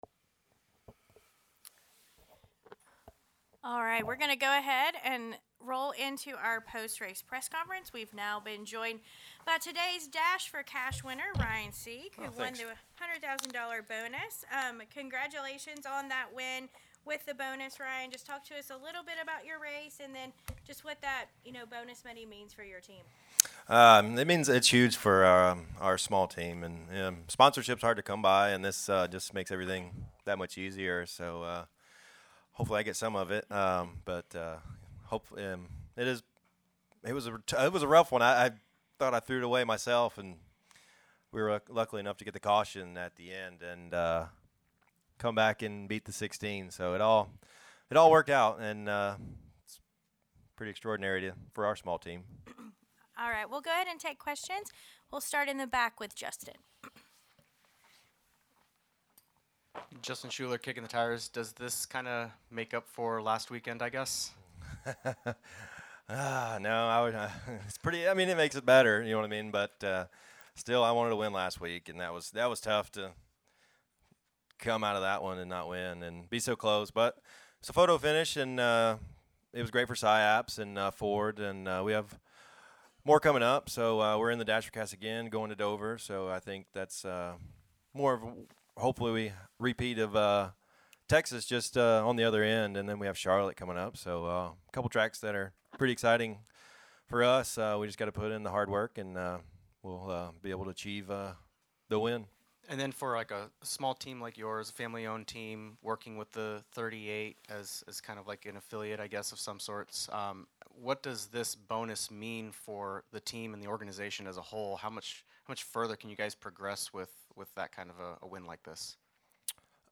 Interviews:
NXS Race Winner – Jesse Love (driver, No. 2 RCR Chevrolet)
Talladega Dash 4 Cash winner – Ryan Sieg (driver, No. 39 Ryan Sieg Racing Ford)